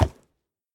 horse_wood1.ogg